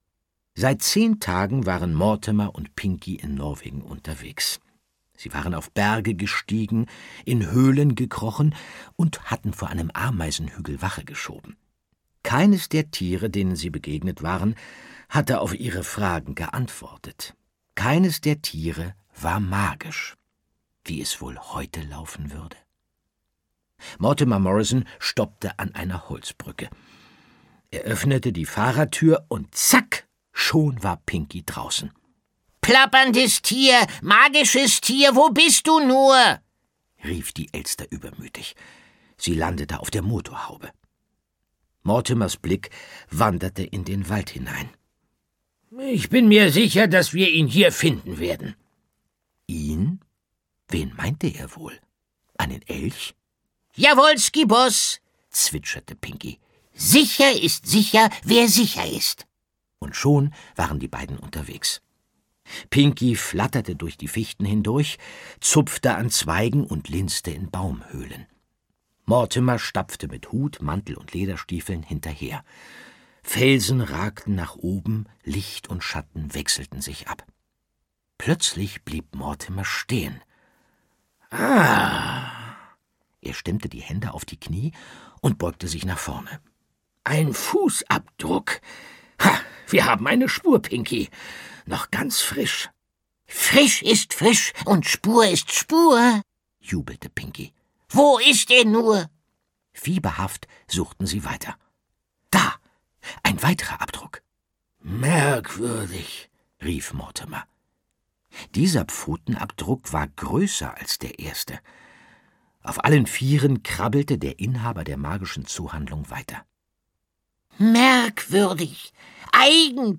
Die Schule der magischen Tiere: Das Hörbuch zum Film - Margit Auer - Hörbuch